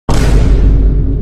Vine Boom Sound Effect Free Download